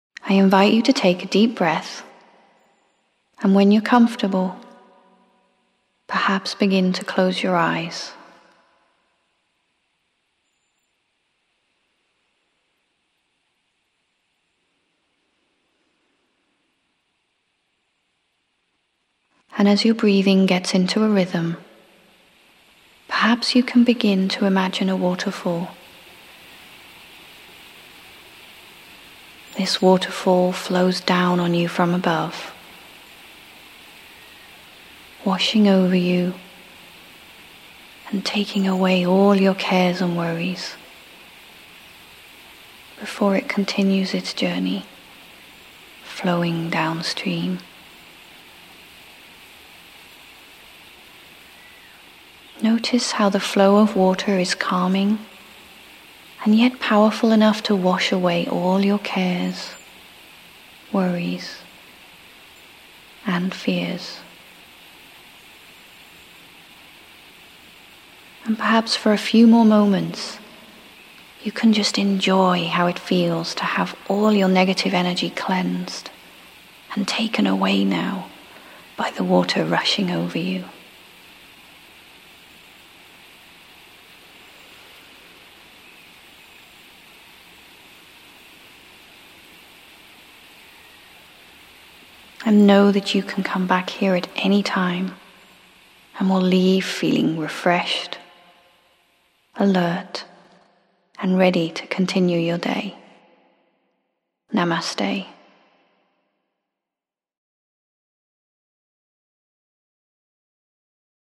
Free Creative Visualization Meditation for Relaxation :